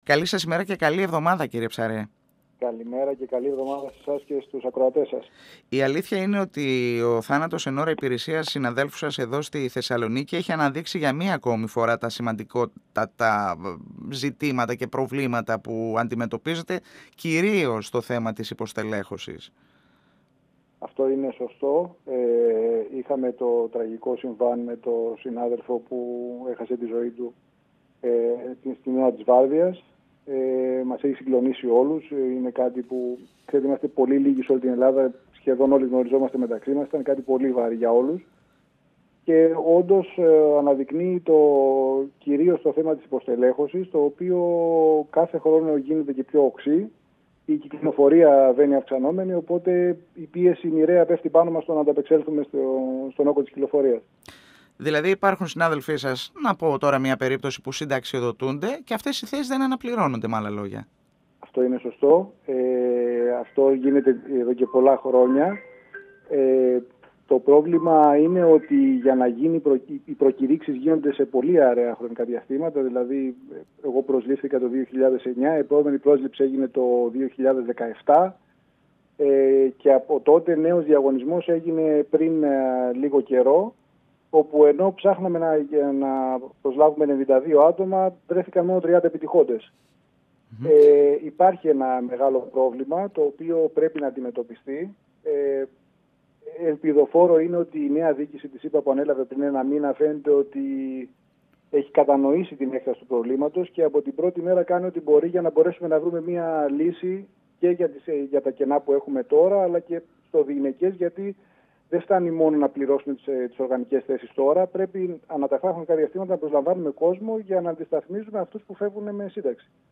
μιλώντας στην εκπομπή «Εδώ και Τώρα» του 102FM της ΕΡΤ3.